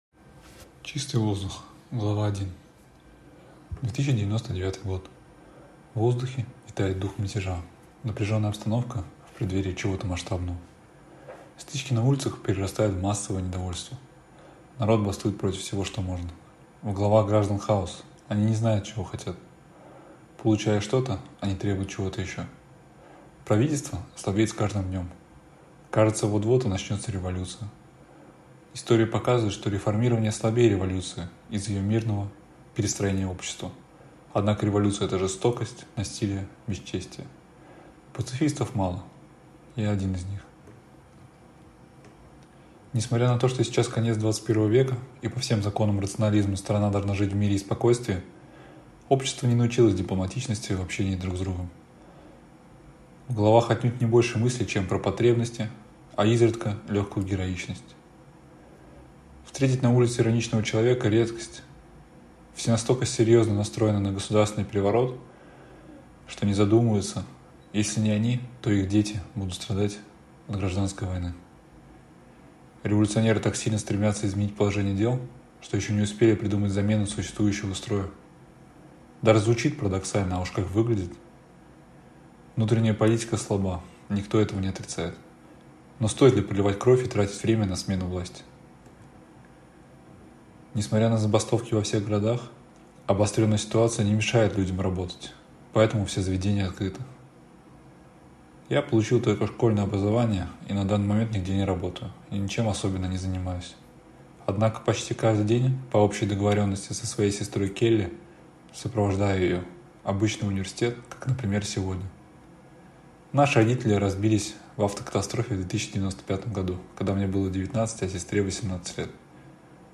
Аудиокнига Чистый воздух | Библиотека аудиокниг